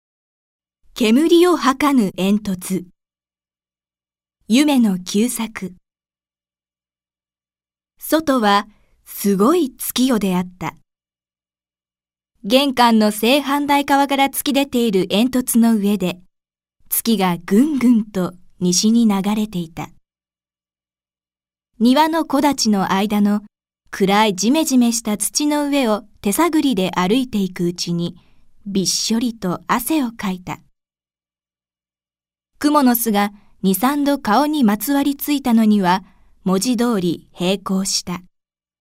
JDirectItems Auction>音樂>CD>朗讀
朗読街道は作品の価値を損なうことなくノーカットで朗読しています。